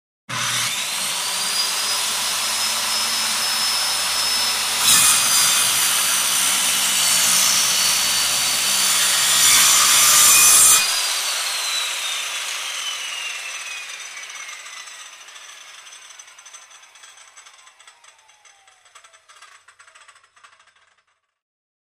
in_circularsaw_cut_01_hpx
Circular saw cuts wood as blade spins. Tools, Hand Wood, Sawing Saw, Circular